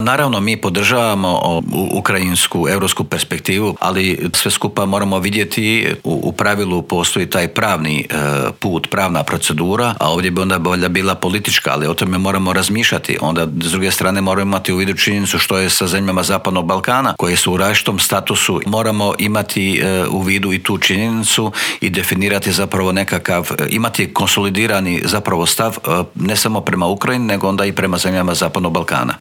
Koliko je Hrvata još u Ukrajini, koliko je ukrajinskih izbjeglica u Hrvatskoj, treba li Ukrajina dobiti ubrzani status kandidata za pristup u EU, ali i kako komentira rusku invaziju i sankcije u Intervjuu tjedna Media servisa otkrio nam je ministar vanjskih i europskih poslova Gordan Grlić Radman.